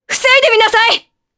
Naila-Vox_Skill5_jp.wav